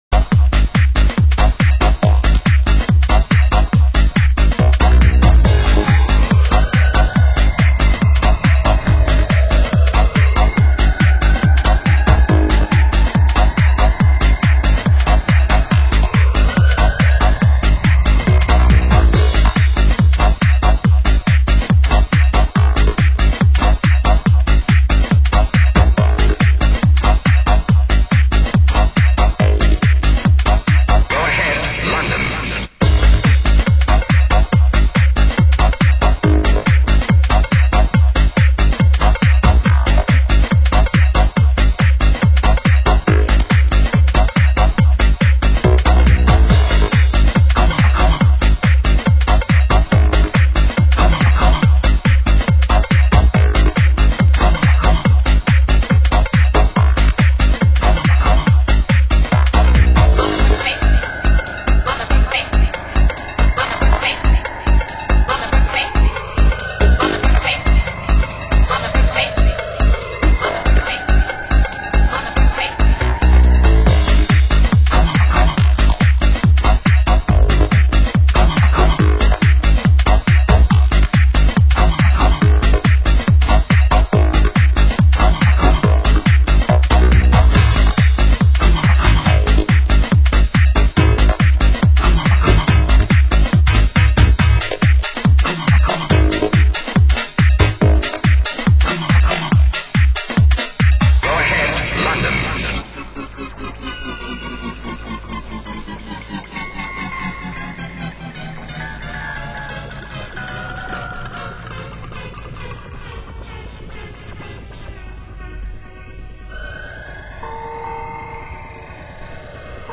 Genre Trance